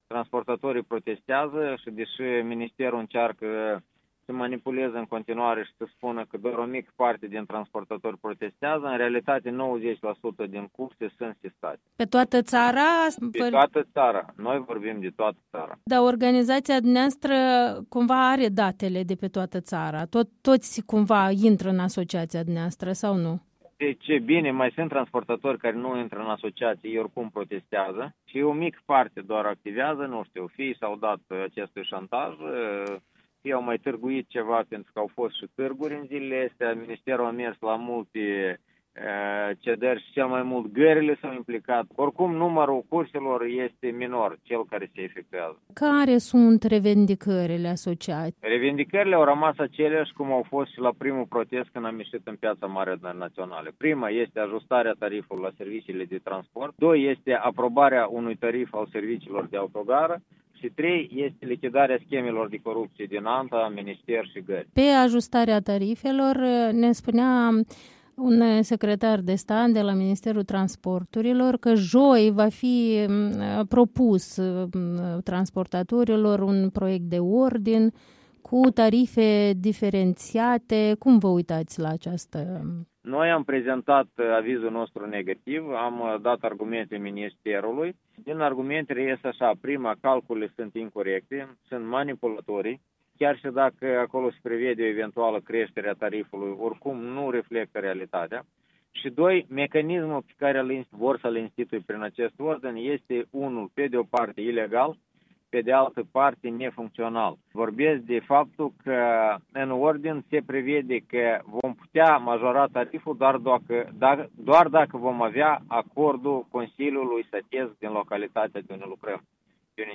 Într-un interviu cu Europa Liberă